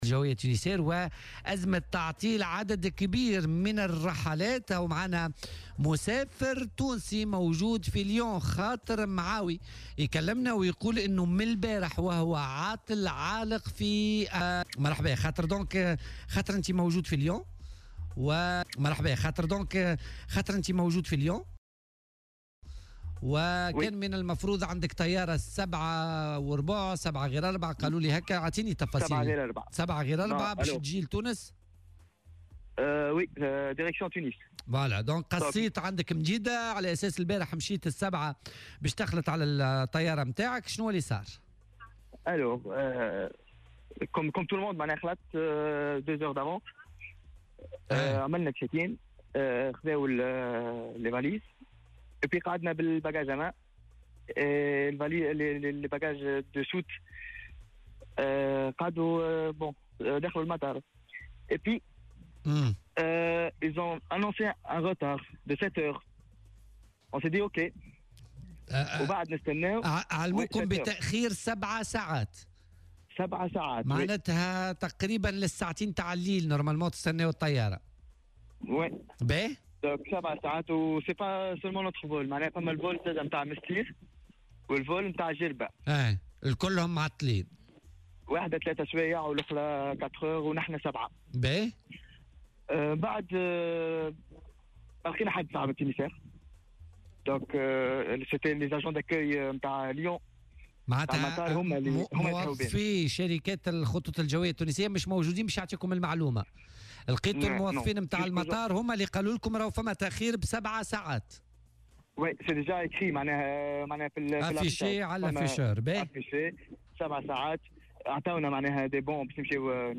وأوضح شاهد العيان في مداخلة هاتفية مع "بوليتيكا" على "الجوهرة أف أم" أنه تم اعلامهم في البداية بتأخير بـ 7 ساعات يشمل 3 رحلات نحو 3 مطارات في تونس إلا ان التأخير مازال متواصلا في ظل غياب موظفي الشركة بالمطار الفرنسي فيما لم يتمكن المسافرون من إلغاء رحلاتهم. وقال إن نحو 400 مسافر على الأقل بصدد الانتظار بينهم مسافرون أجانب اختاروا الناقلة الوطنية لقضاء عطلتهم في تونس.